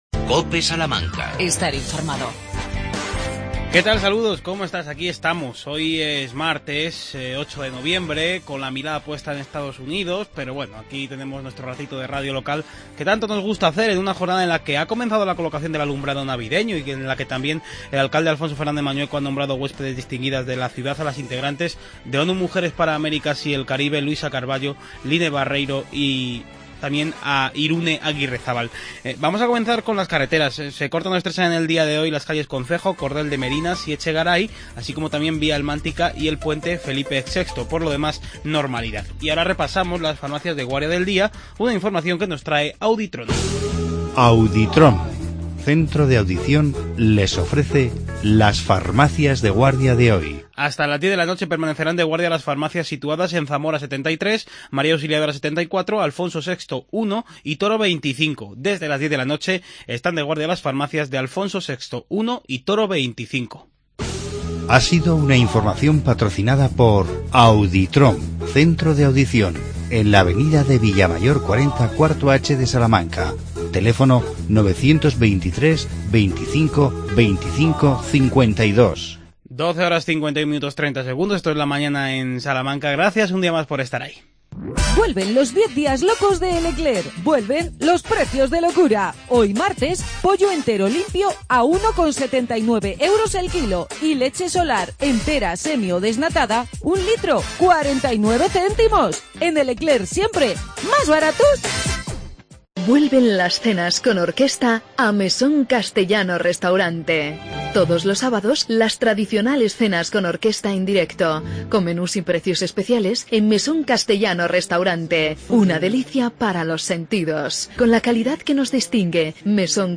Redacción digital Madrid - Publicado el 08 nov 2016, 13:02 - Actualizado 19 mar 2023, 04:43 1 min lectura Descargar Facebook Twitter Whatsapp Telegram Enviar por email Copiar enlace El concejal de Empleo Enrique Sánchez Guijo nos ha hablado del programa Millenials y del nuevo espacio municipal de coworking.